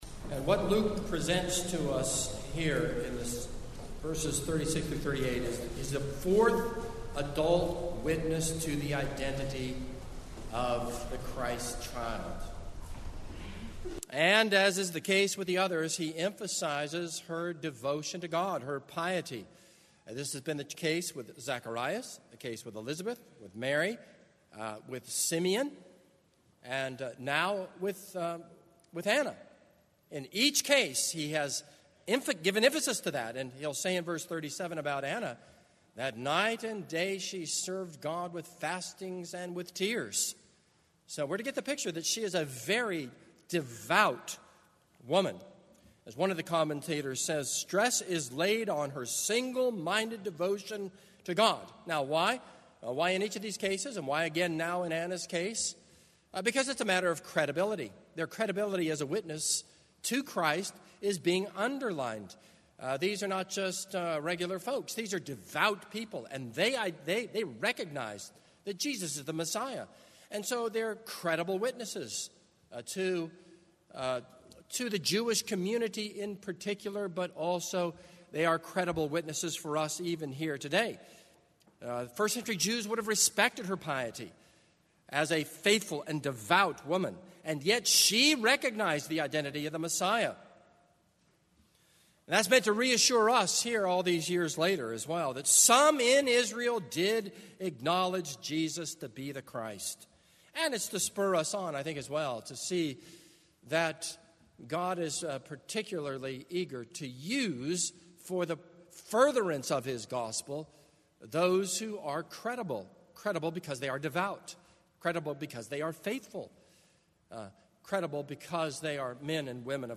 This is a sermon on Luke 2:36-38.